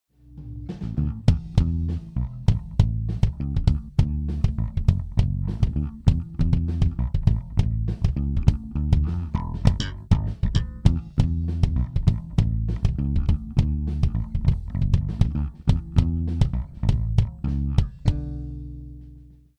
Записали инструментальную композицию, сижу, свожу.